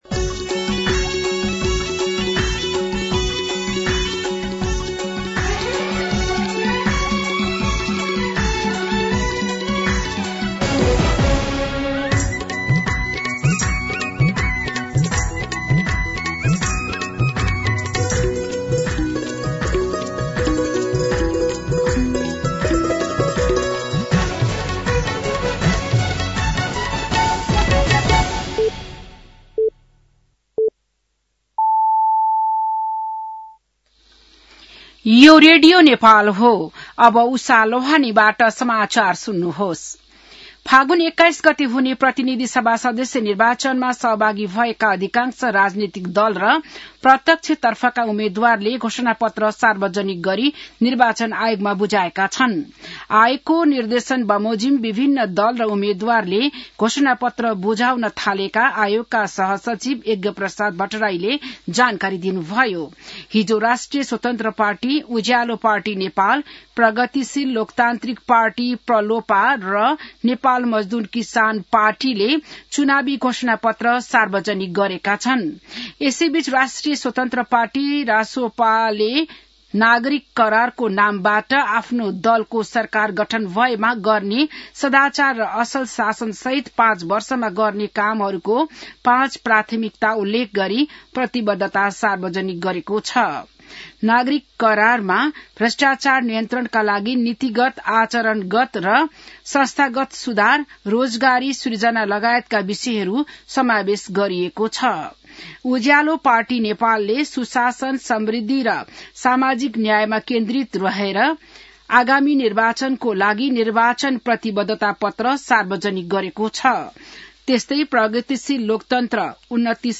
बिहान ११ बजेको नेपाली समाचार : ४ फागुन , २०८२
11-am-Nepali-News-4.mp3